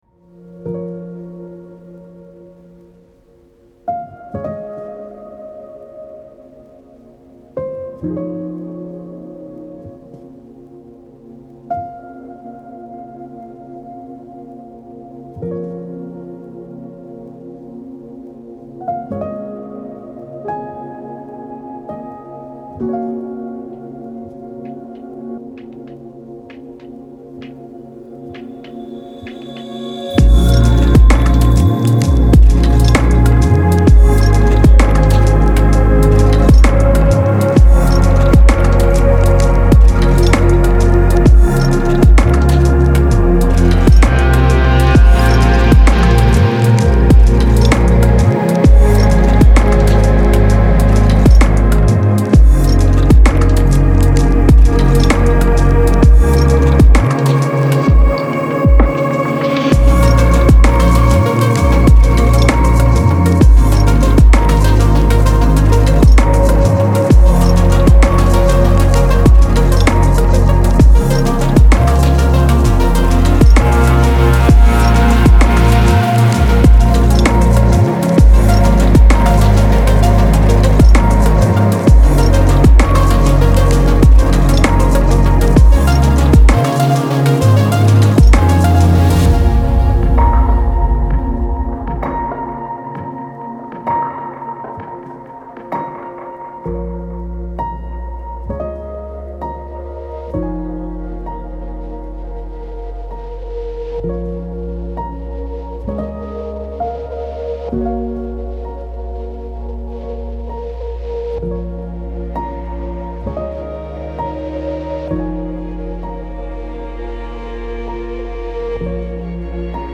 это мощная пост-хардкор композиция
а также поклонникам жанров пост-хардкор и эмо.